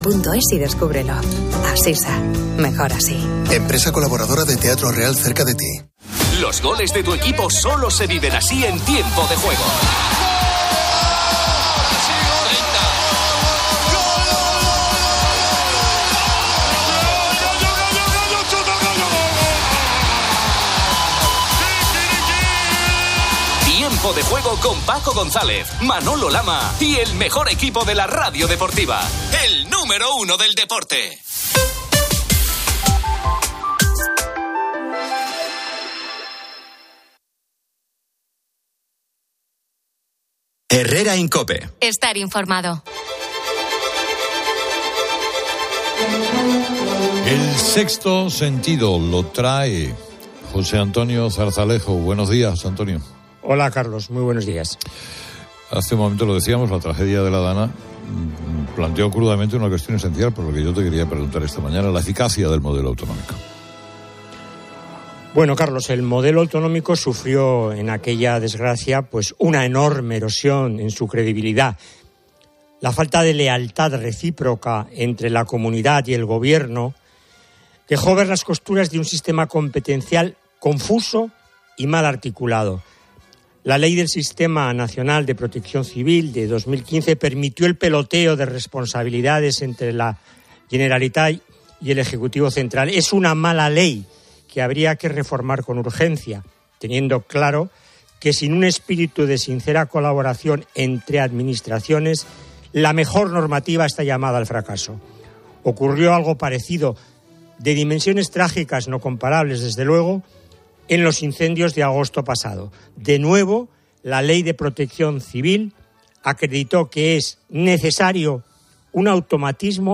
Expertos y colaboradores analizan la ineficacia del modelo autonómico y la falta de responsabilidad política en la gestión de la crisis, abogando por la creación de una Agencia Nacional de Emergencias. El programa aborda también la polémica reforma del enjuiciamiento criminal, que otorga mayor poder al fiscal, generando críticas sobre la politización de la justicia.
La emisión incluye actualizaciones de tráfico y diversas promociones comerciales.